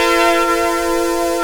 Index of /90_sSampleCDs/USB Soundscan vol.03 - Pure Electro [AKAI] 1CD/Partition D/06-MISC
SYNTH     -R.wav